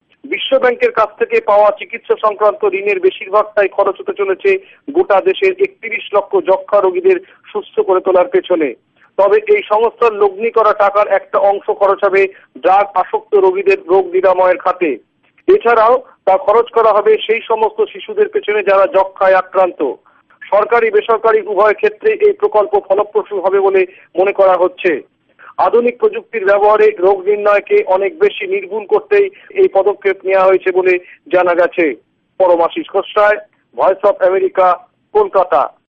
ভয়েস অব আমেরিকার কোলকাতা সংবাদদাতাদের রিপোর্ট: